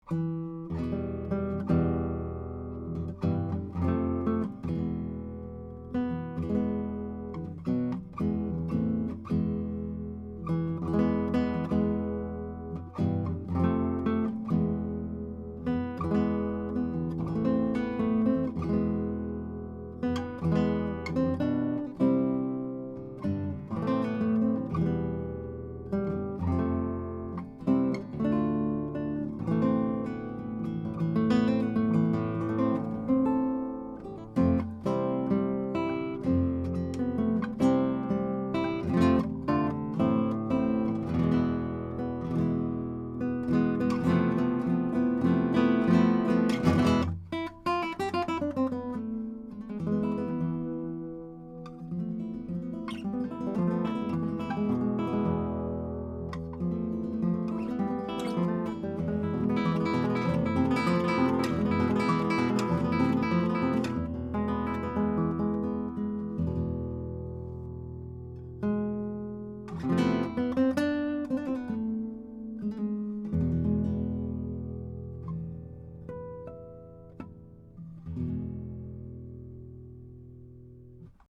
Here are 14 MP3s of the Chandler TG Channel using a Neumann TLM67 on Milagro 10-String Classical Harp Guitar into a Metric Halo ULN-8 converter, to Logic, with no additional EQ or any other effects: